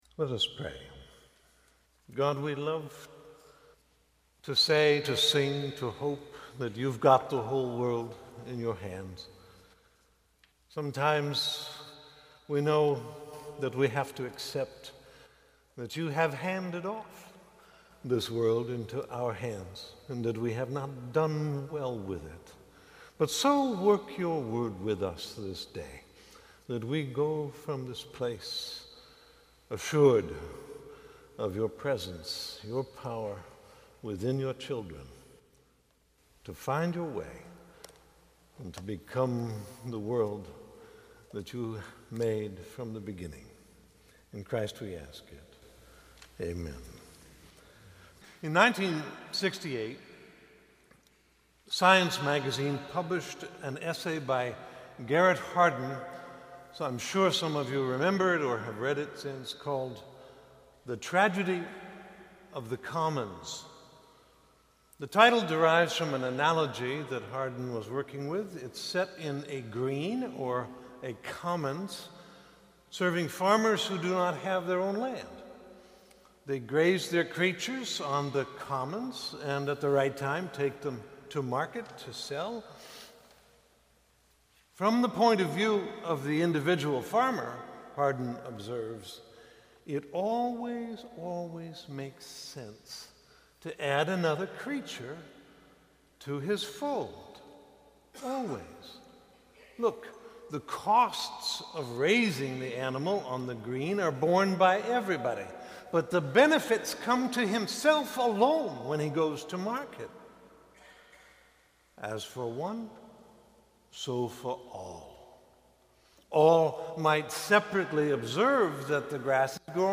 sermon 2012